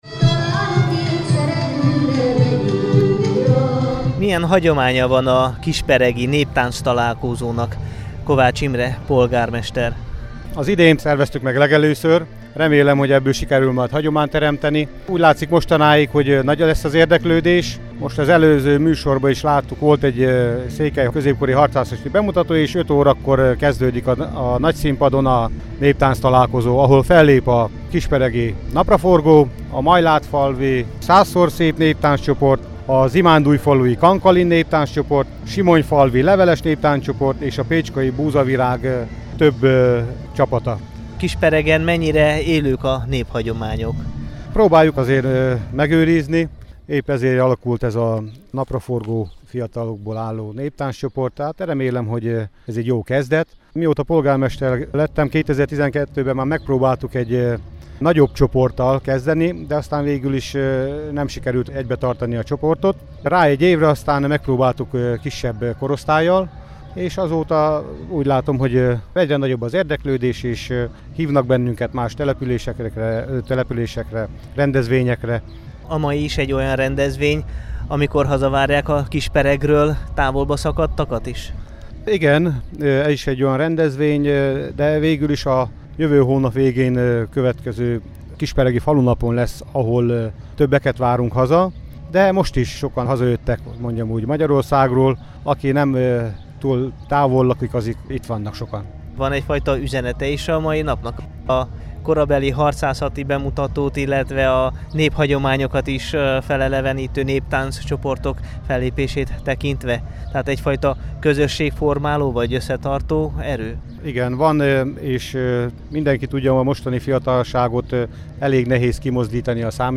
Első ízben rendezték meg Kisperegen a Néptánctalálkozót. A szombati rendezvényen a helyi együttes mellett a környékbeli népi együttesek adtak ízelítőt tudásukból és repertoárjukból.
kisperegi_neptanctalalkozo_2015.mp3